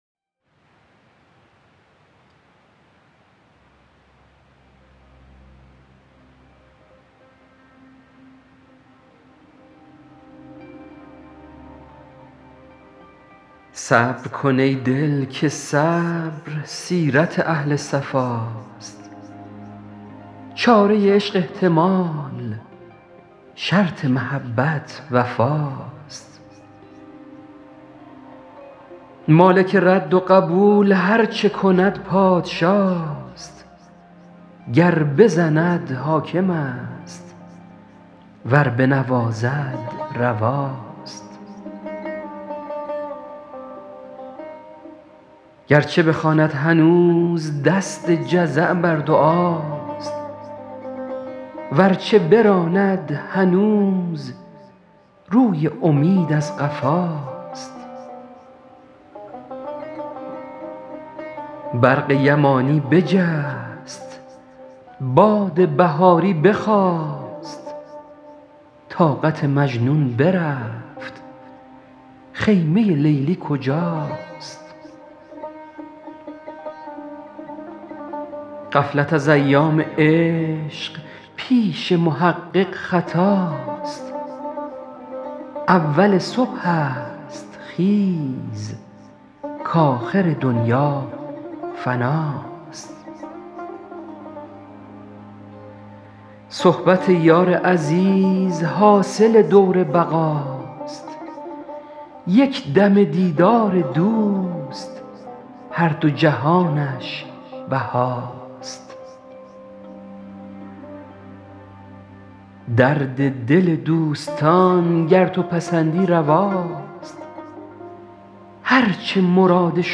سعدی » دیوان اشعار » غزلیات » غزل شمارهٔ ۴۸ با خوانش